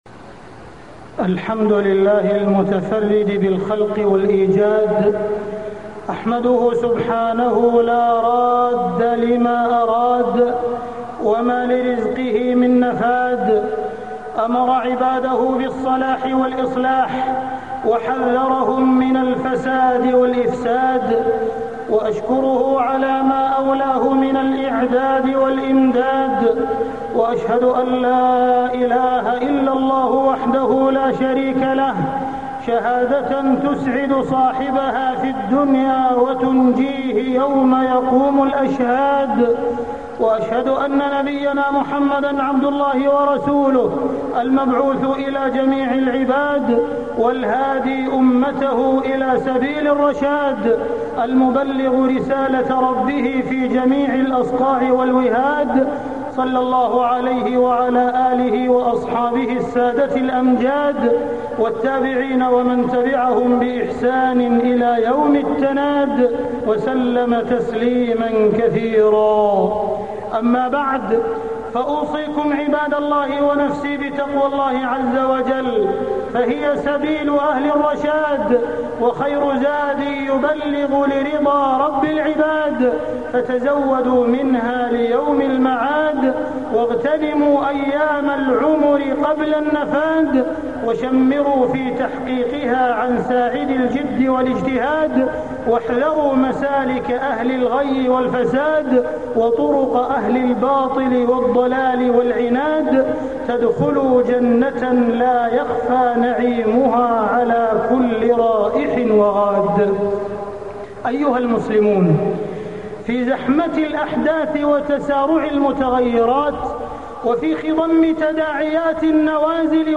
تاريخ النشر ٦ جمادى الآخرة ١٤٢٥ هـ المكان: المسجد الحرام الشيخ: معالي الشيخ أ.د. عبدالرحمن بن عبدالعزيز السديس معالي الشيخ أ.د. عبدالرحمن بن عبدالعزيز السديس المخرج من فتنتي التغريب والتخريب